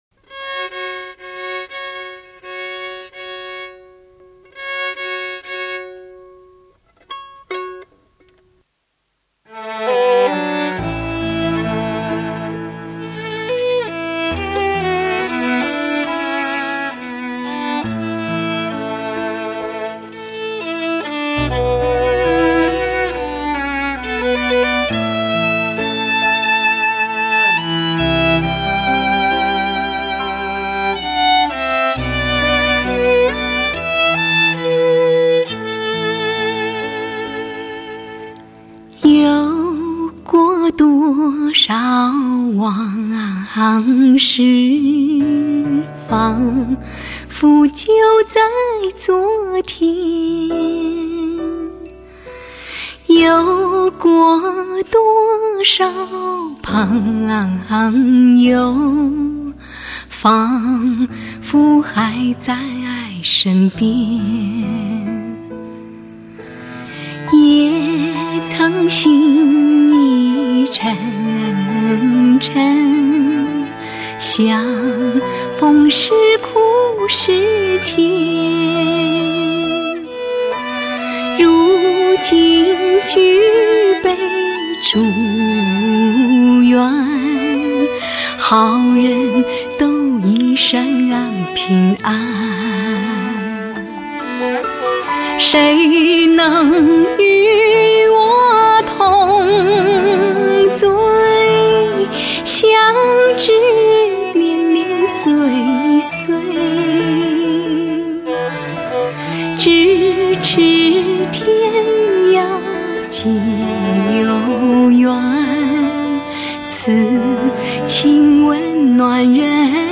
翻唱经典老歌
总的来说整张碟的录音制作是相当发烧的。